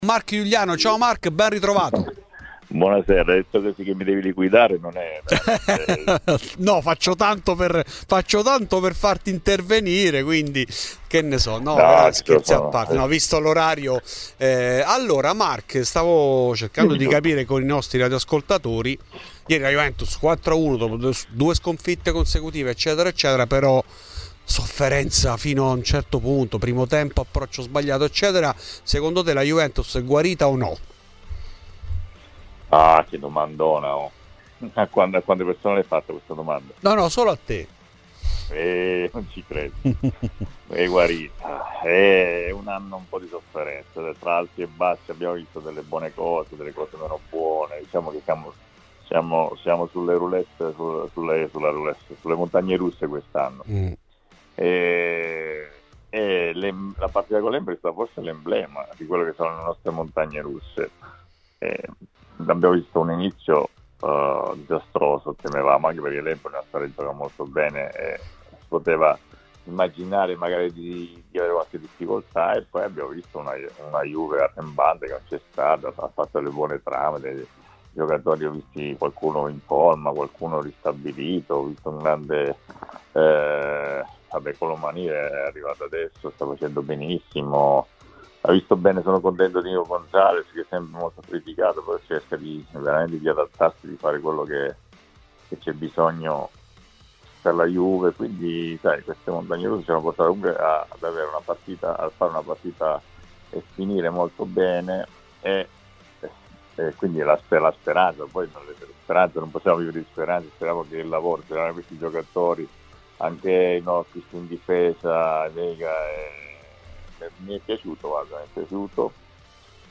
In ESCLUSIVA a Fuori di Juve Mark Iuliano. Iuliano risponde così quando gli viene chiesto lo stato di salute della Juve dopo il successo contro l'Empoli".